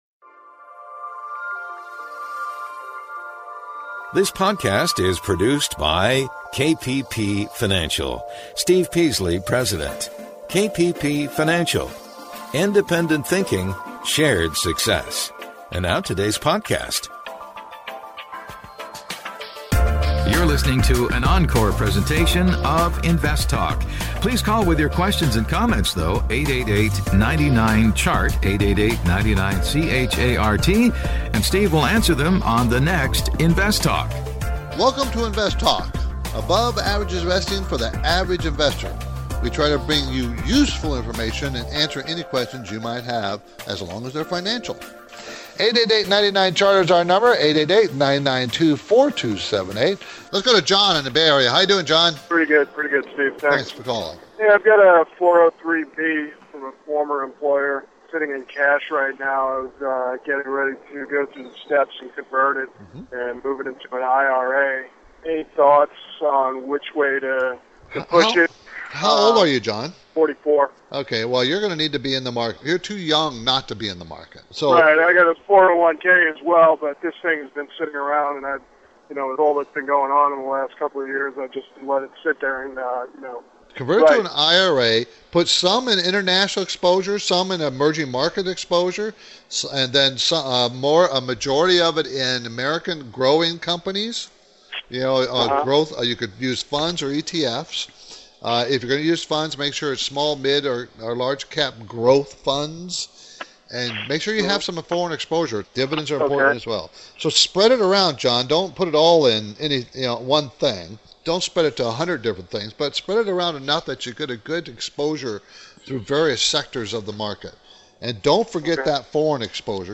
In this compilation program
field a variety of finance and investment questions from callers across the United States and around the world.